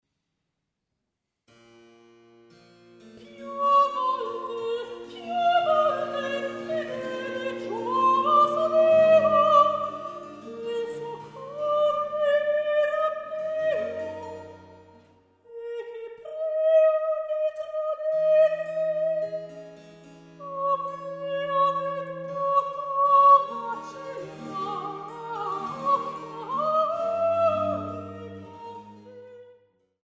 Sopran
Cembalo und musikalische Leitung
Ensemble für Alte Musik